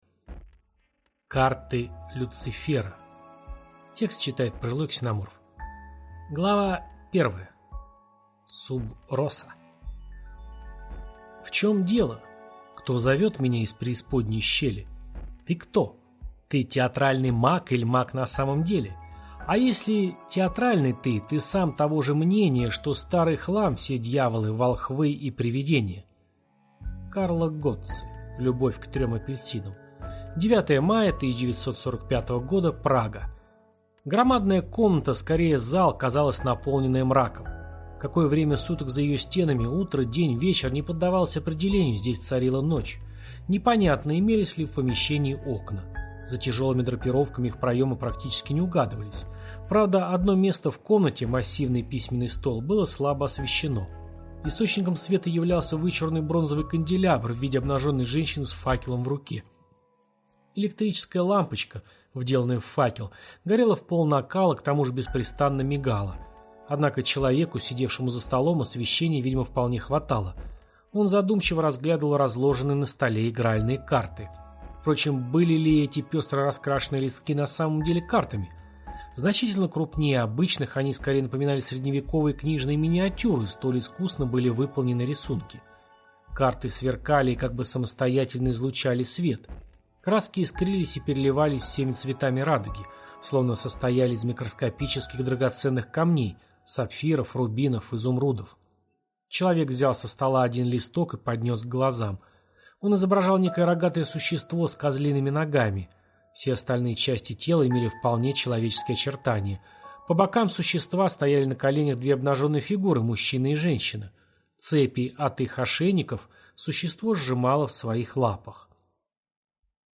Аудиокнига Карты Люцифера | Библиотека аудиокниг